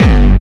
VEC3 Bassdrums Dirty 21.wav